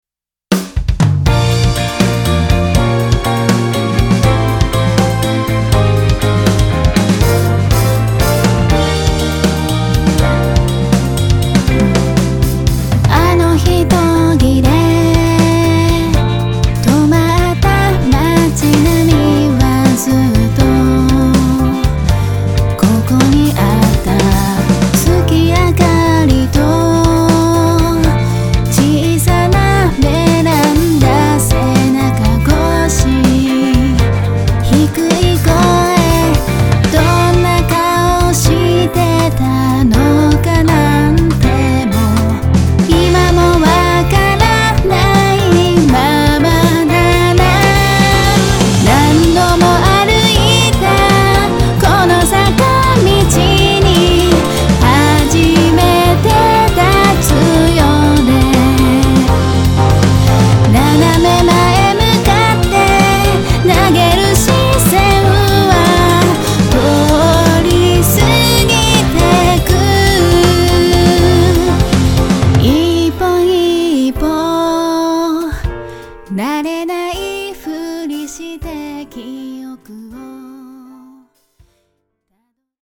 ポップでキュートでちょっぴりビターな全2曲を収録。